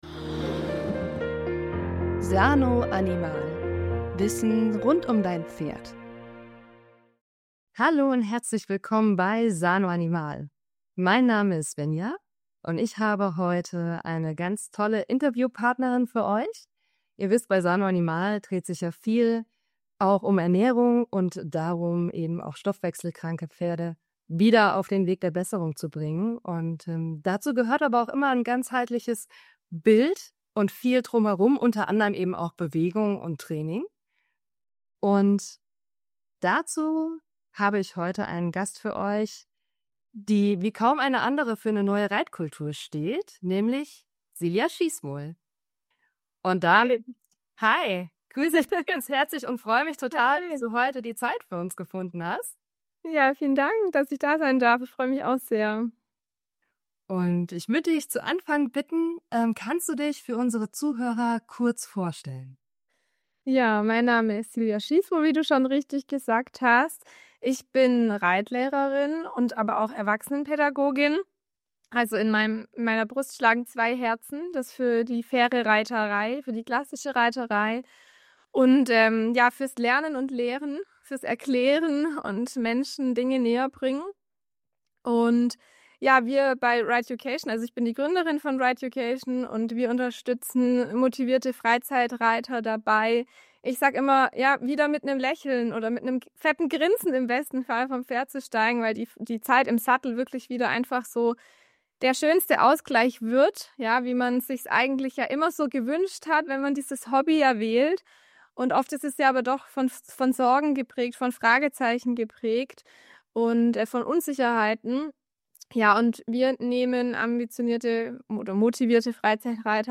In diesem Expertentalk erfährst du: